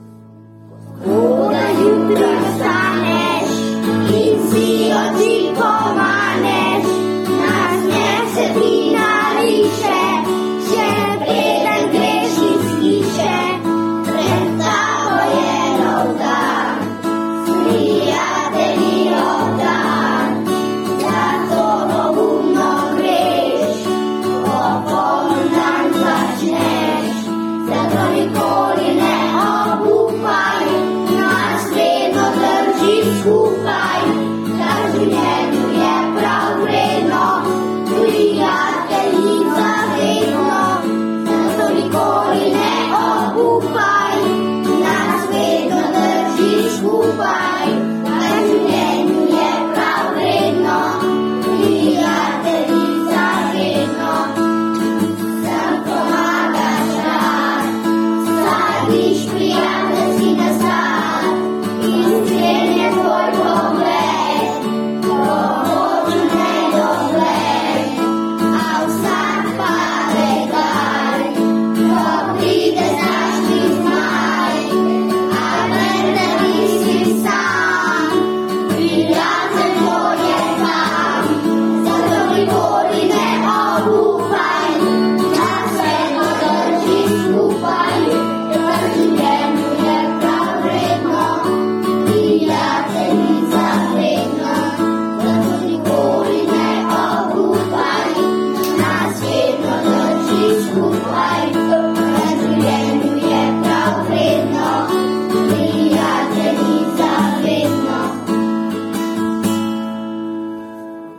Pesem so zapeli otroci